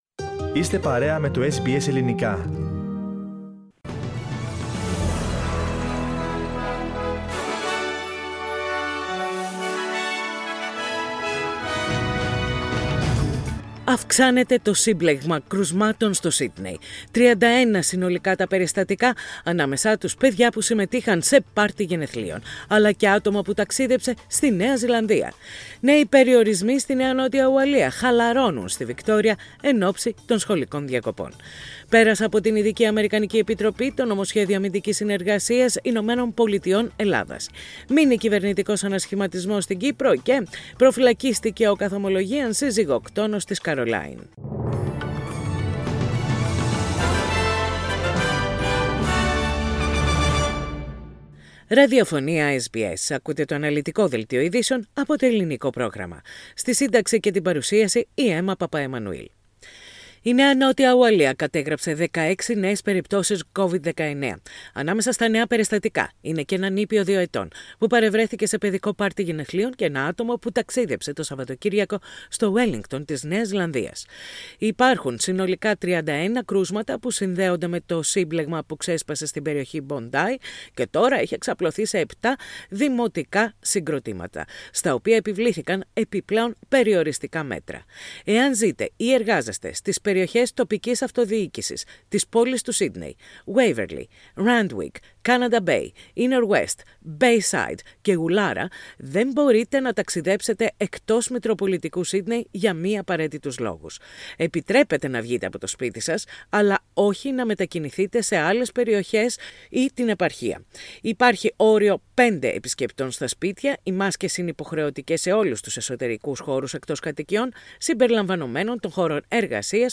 News in Greek - Wednesday 23.6.21
Main news of the day from SBS Radio Greek.